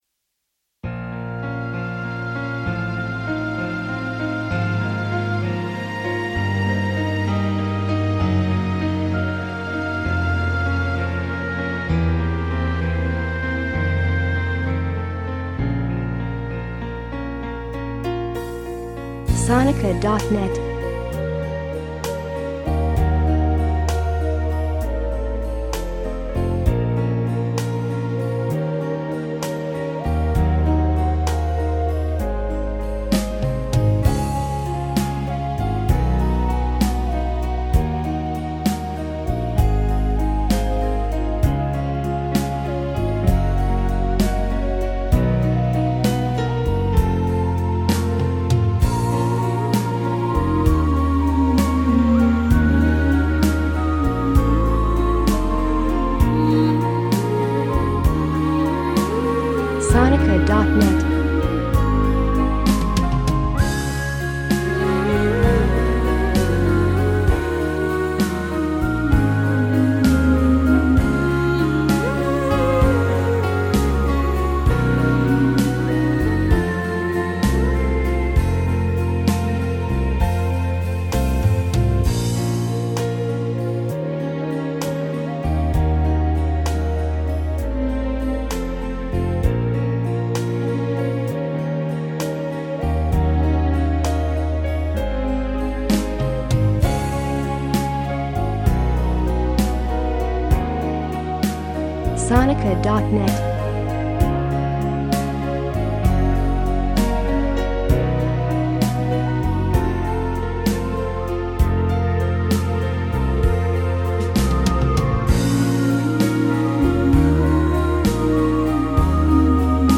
A sonic journey through emotional landscapes.